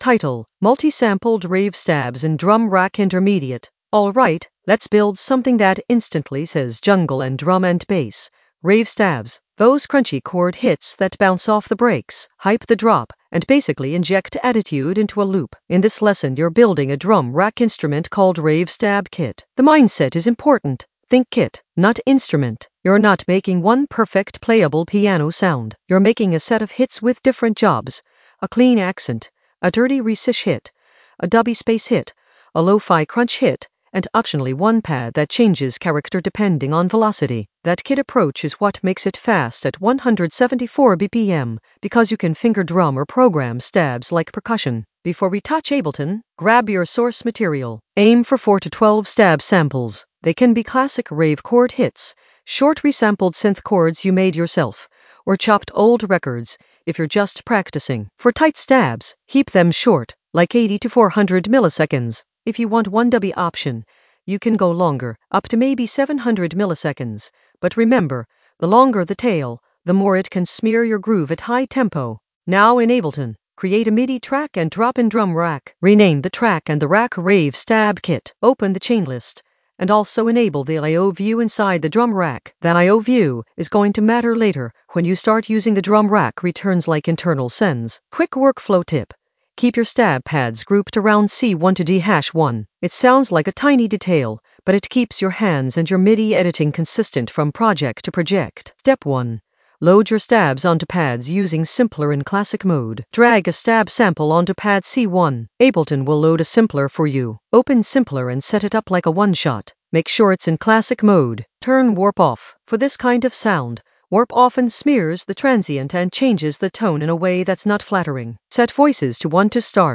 Narrated lesson audio
The voice track includes the tutorial plus extra teacher commentary.
An AI-generated intermediate Ableton lesson focused on Multisampled rave stabs in Drum Rack in the Sound Design area of drum and bass production.